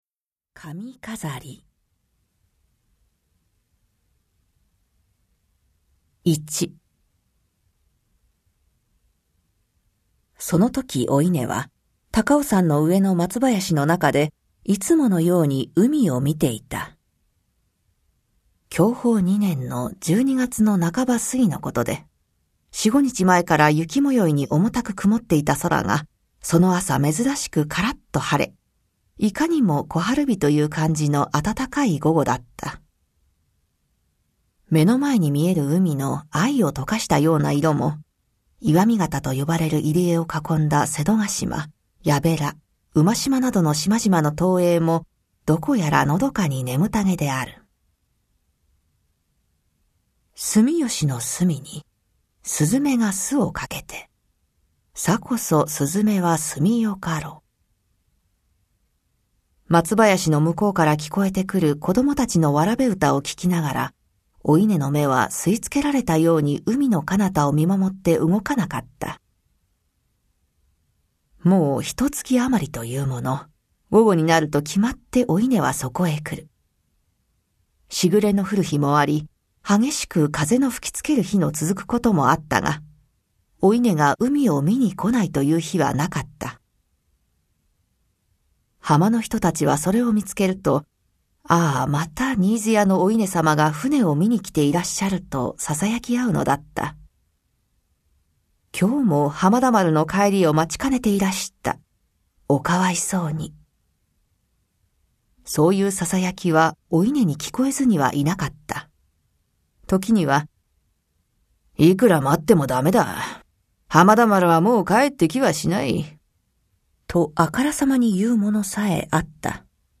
[オーディオブック] 髪かざり